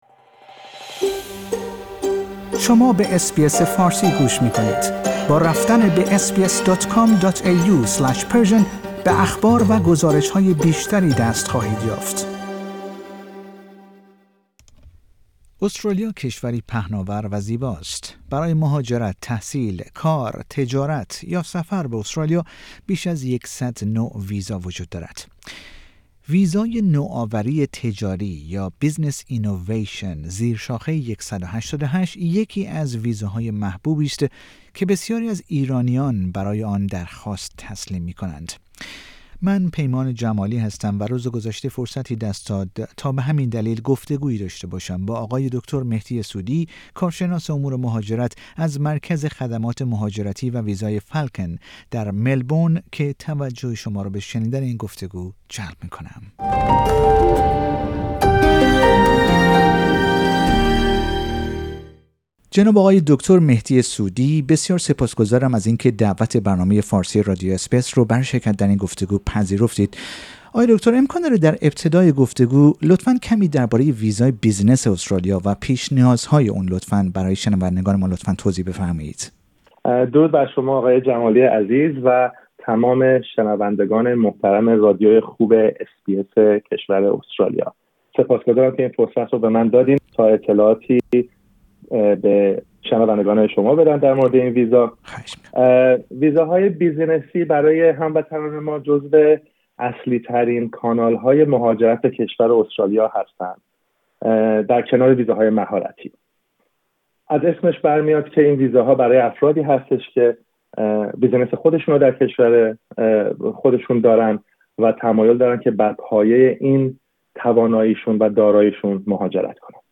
در گفتگو با برنامه فارسی رادیو اس بی اس درباره این ویزا، پیش نیازهای آن و امکان تبدیل کردن آن به اقامت دائم و در نهایت شهروندی استرالیا توضیح می دهد.